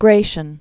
(grāshən, -shē-ən) Full name Flavius Augustus Gratianus.